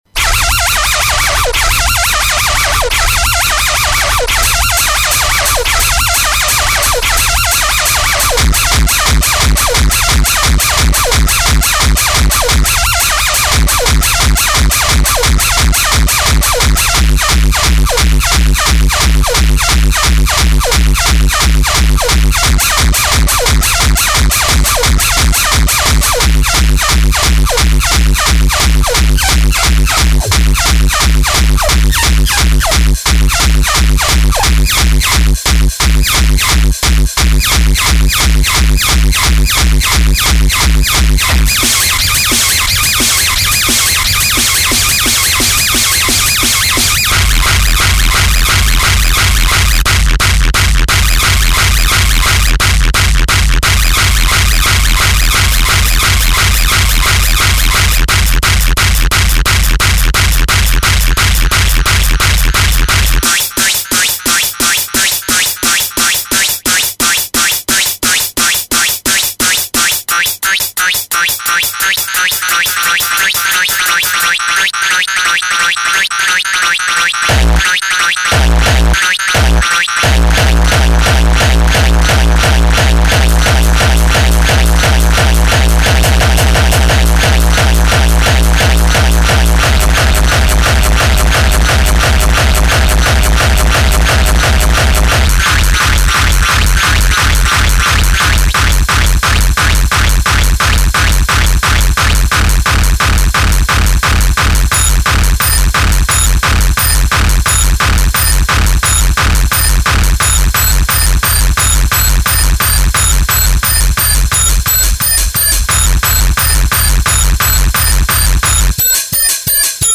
Hardtekk, Experimental Hardtekk, Hardcore
Korg EMX - Korg ESX - Fruity Loops 9